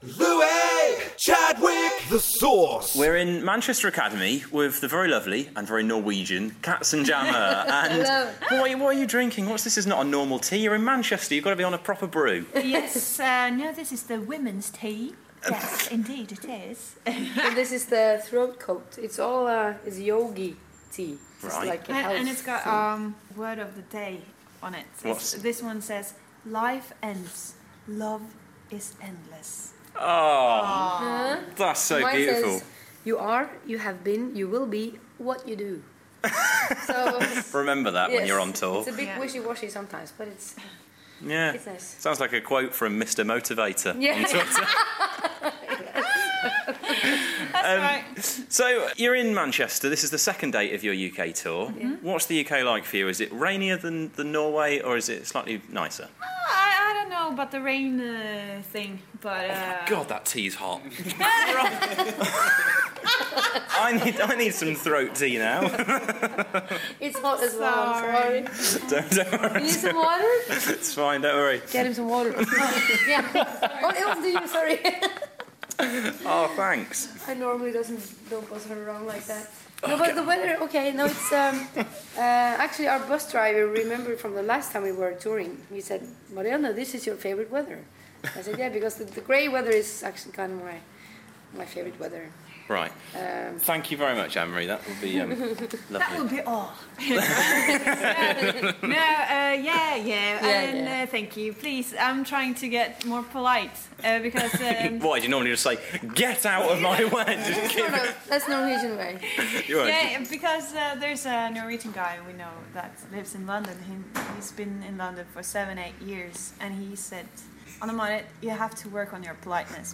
Nov12 - Katzenjammer in conversation